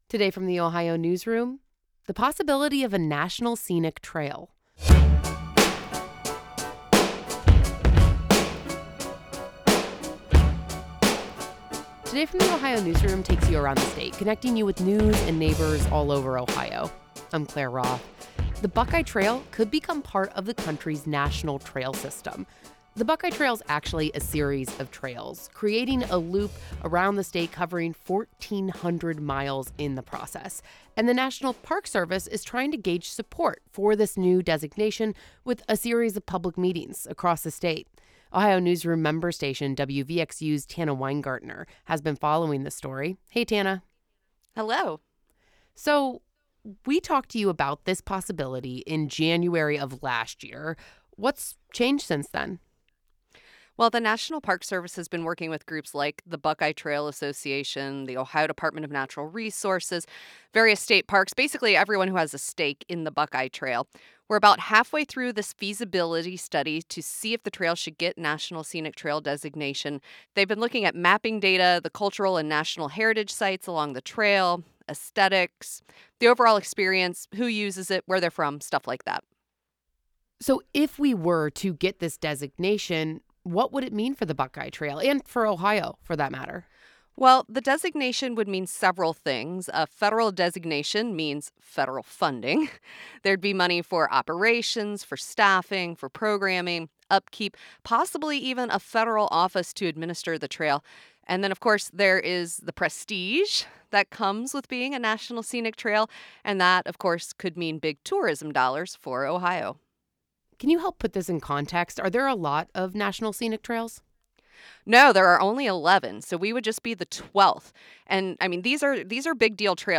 This interview has been lightly edited for clarity and brevity.